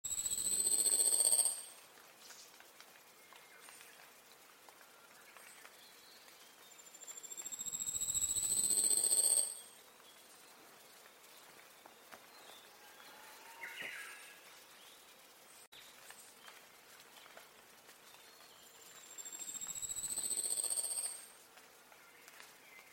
鳴 き 声：晩夏にはチャッ、チャッというウグイスに似た声で鳴く。
鳴き声２
ヤブサメ　完
yabusame02.mp3